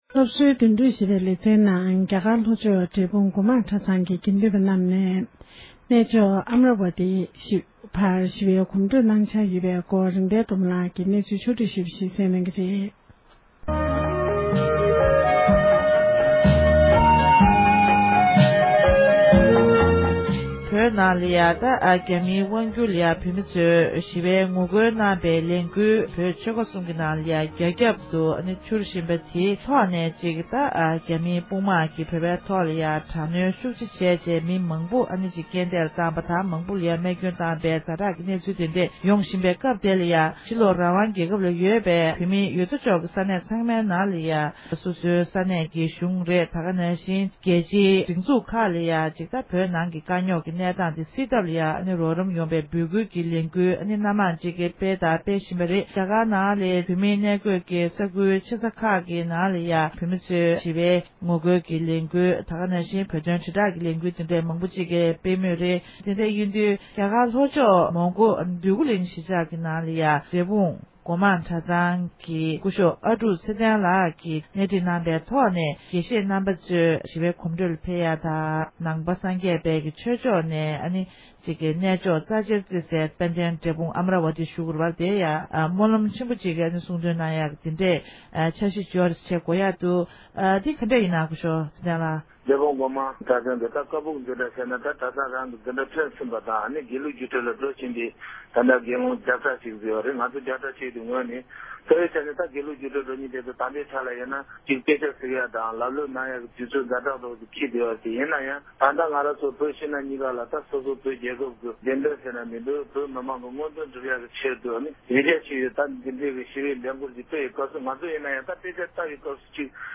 སྒྲ་ལྡན་གསར་འགྱུར། སྒྲ་ཕབ་ལེན།
གནས་འདྲི